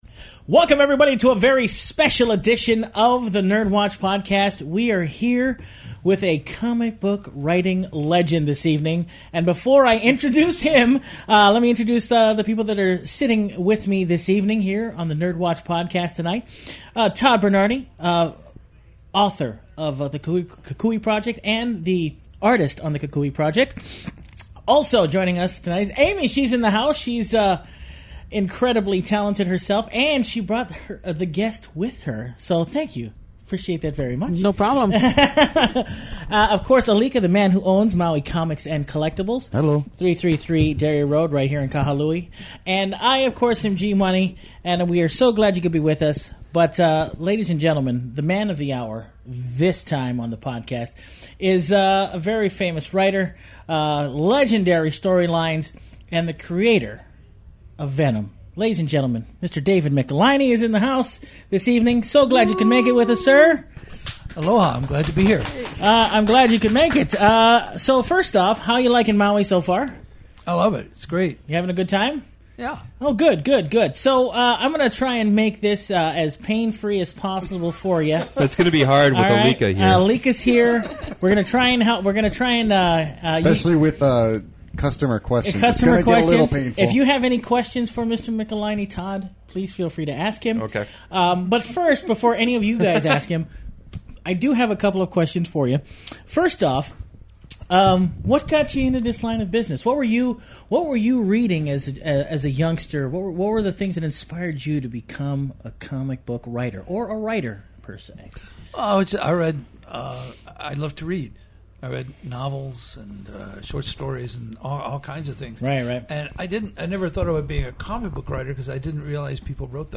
Update: Recently we here at the NERDWatch have been experiencing issues with our editing tools. We hope the raw audio quality here will suffice until we are back up and fully operational